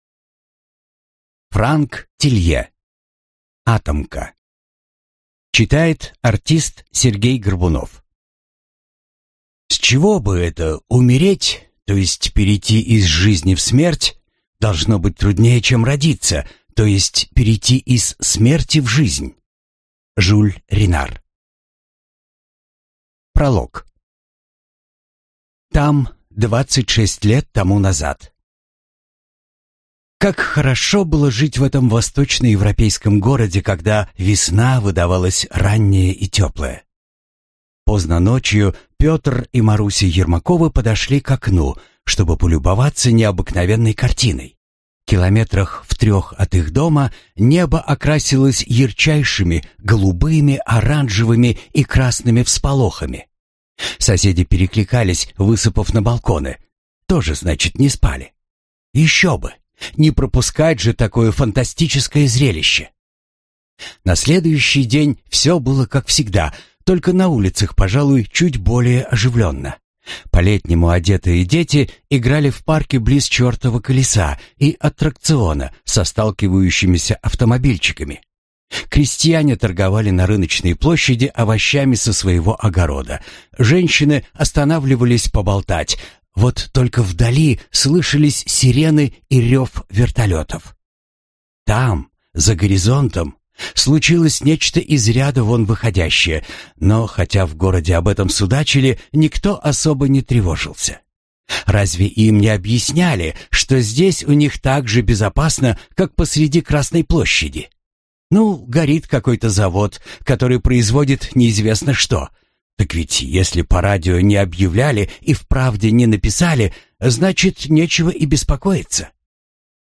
Аудиокнига Атомка | Библиотека аудиокниг
Прослушать и бесплатно скачать фрагмент аудиокниги